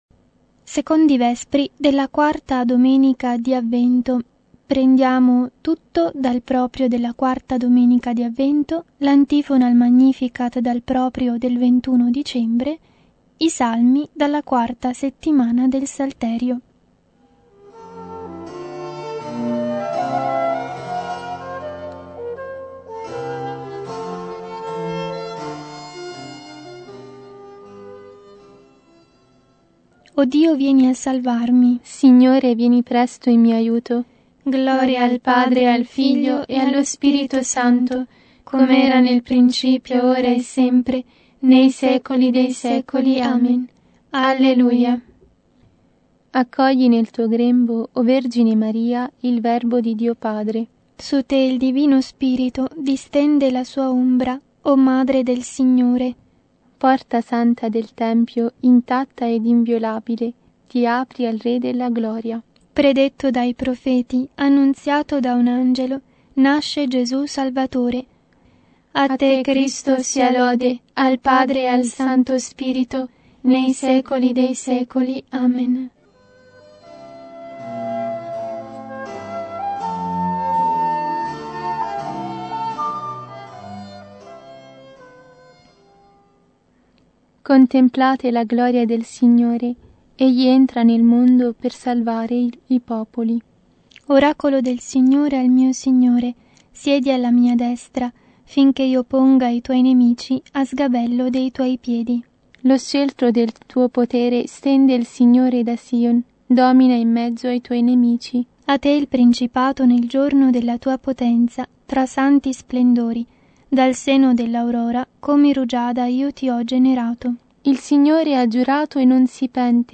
Vespri – 21 Dicembre